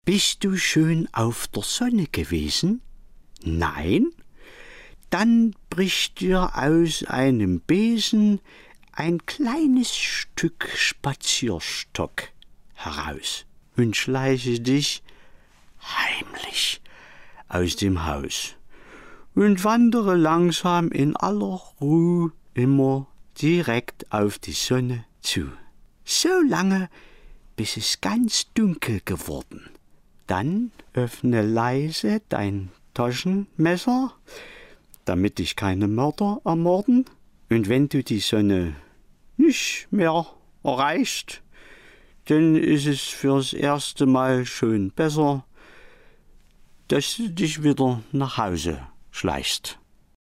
Geschichten, Kabarettszenen, Parodien und Minihörspiele.